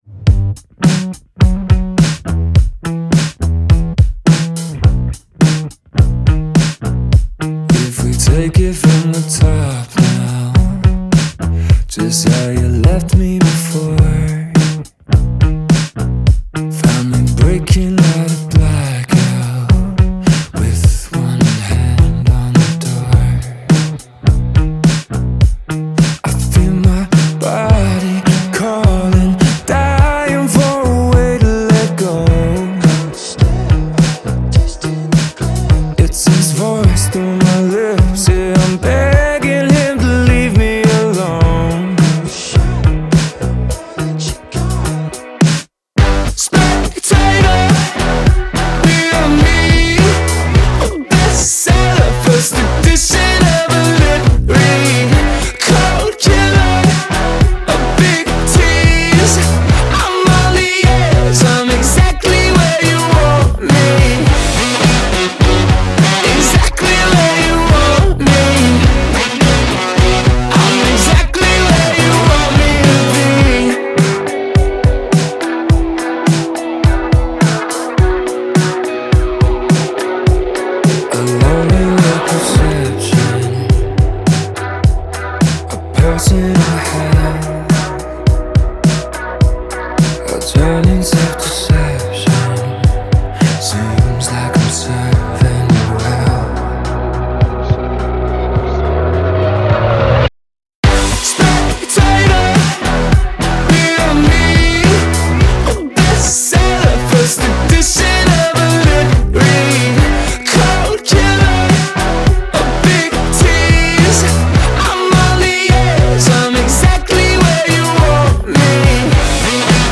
BPM105-105
Audio QualityPerfect (High Quality)
Indie song for StepMania, ITGmania, Project Outfox
Full Length Song (not arcade length cut)